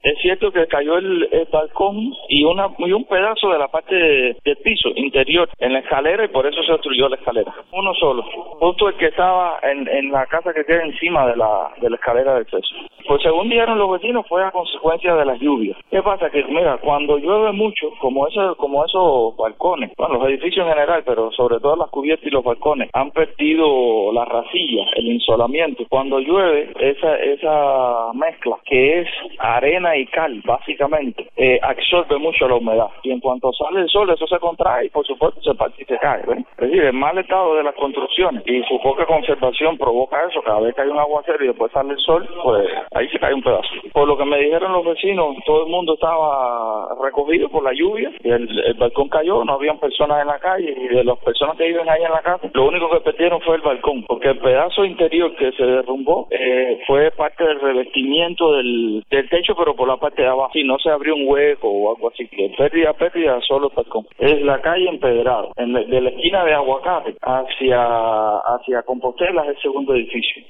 desde La Habana Vieja.